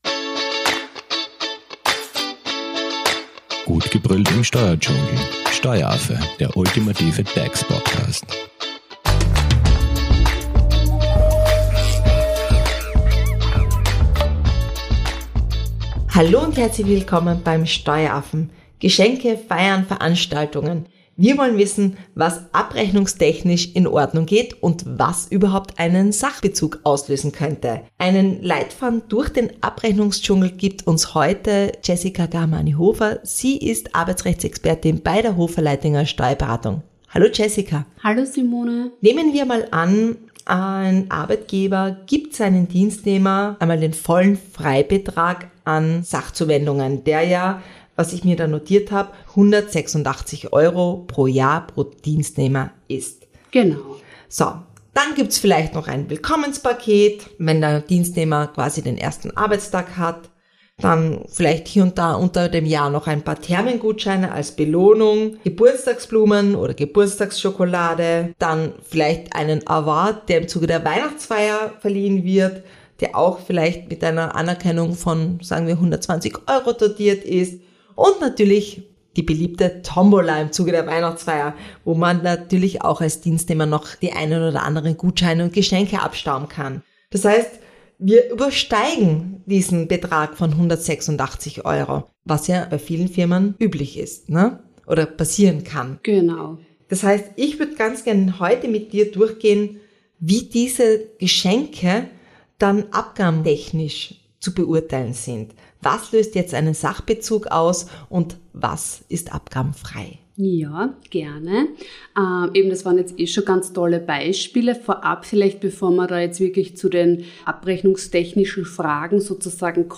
Euer Podcast für steuerliche und arbeitsrechtliche Fragen.